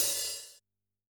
Index of /musicradar/Kurzweil Kit 03
CYCdh_Kurz03-HfHat.wav